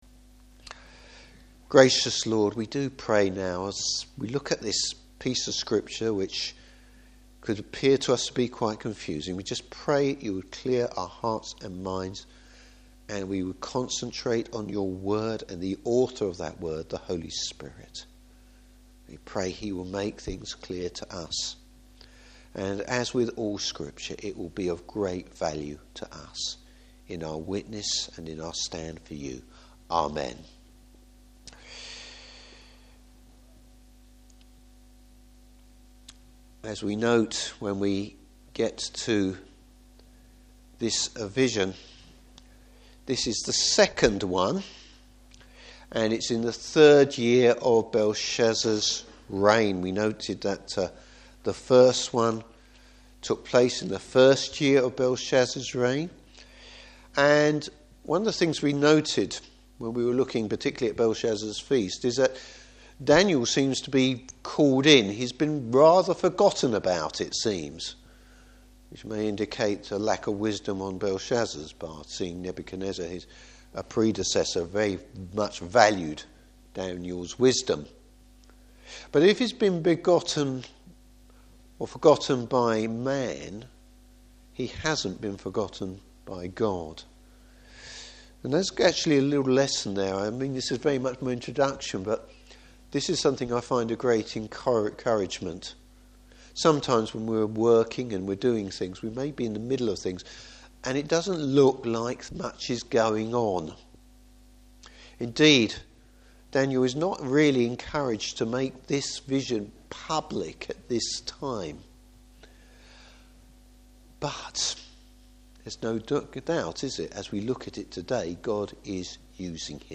Service Type: Evening Service Daniel sees the real deal when it comes to world history. Topics: The sovereignty of God.